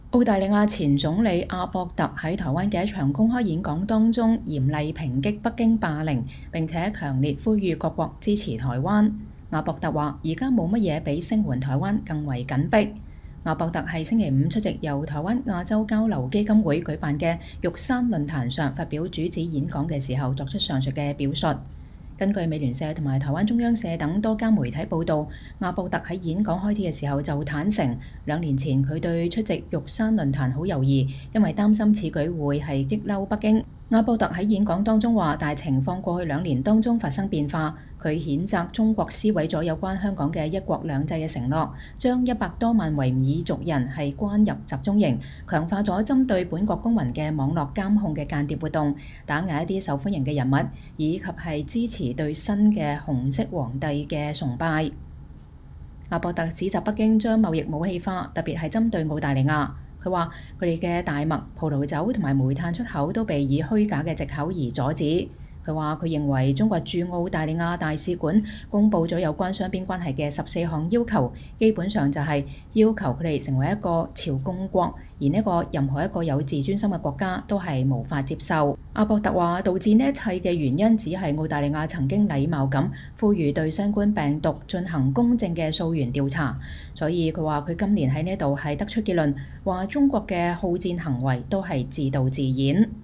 澳大利亞前總理阿博特（Tony Abbott）在台灣的一場公開演講中嚴厲抨擊北京霸凌，並強烈呼籲各國支持台灣。